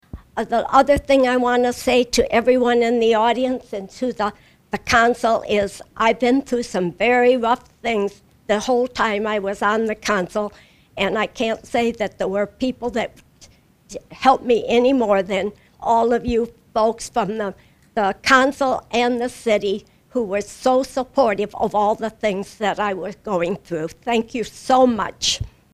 Three Clinton City Council members and the Mayor who were not re-elected in November were honored at the final meeting of the year.